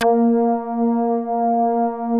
P.5 A#4.8.wav